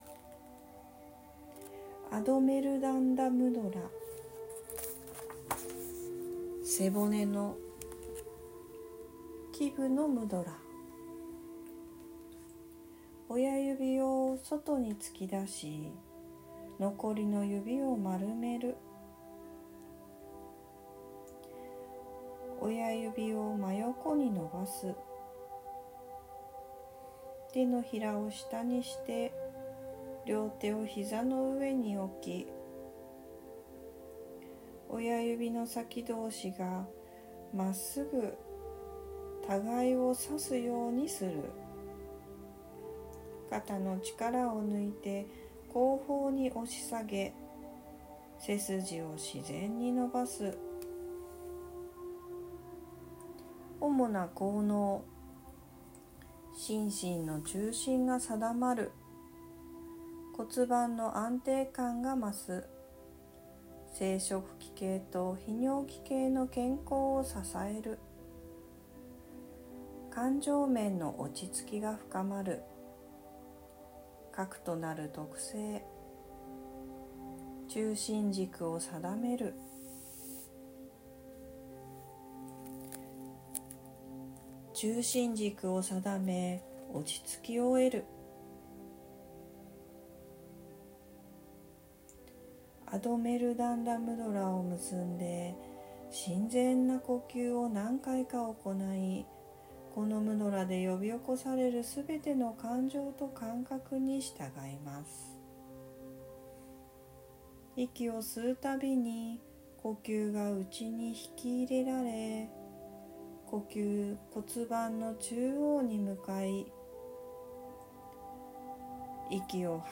覚えられたら、間のガイドの音声を聞かずにポーズをとり文言を３回唱えても効果はあるように感じます。